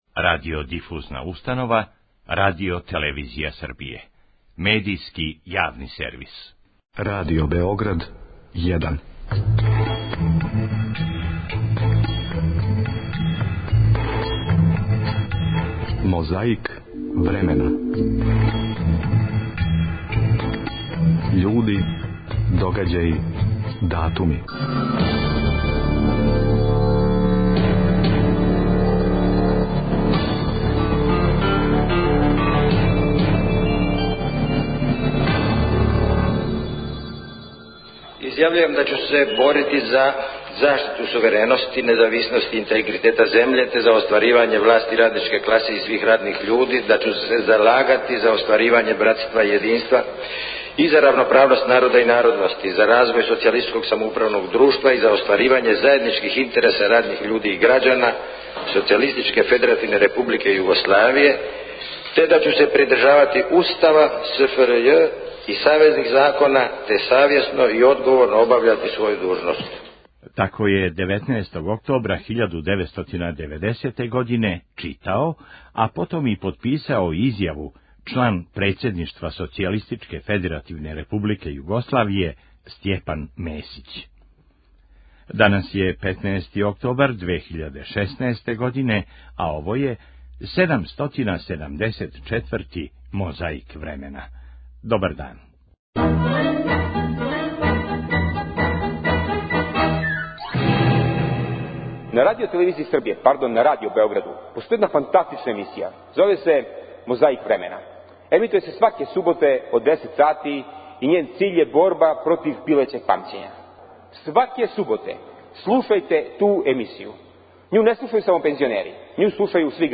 Војна парада названа „Корак победника“ одржана је у Београду 16. октобра 2014. године, а поводом 70 година ослобођења Београда у Другом светском рату. Сутрадан се овако извештавало у 'Новостима дана' Првог програма Радио Београда.
Скандирало се, певало, клицало.